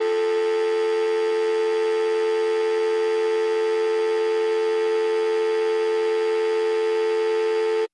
Telephone, Dial Tone